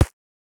Body armor 2.wav